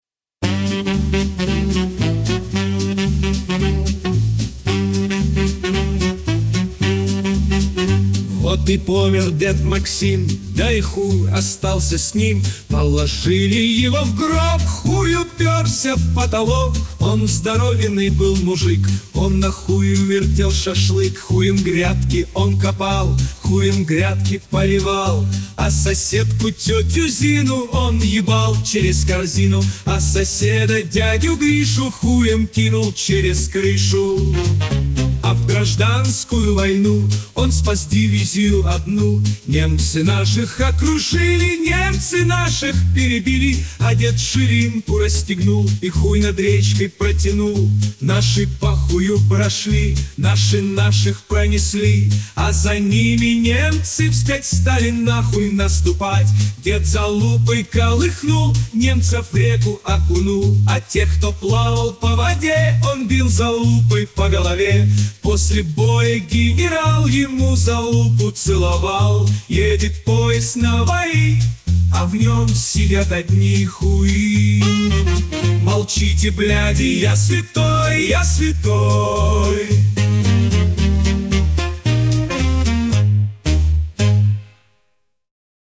(2.23 MB, Дед Максим (Джаз).mp3)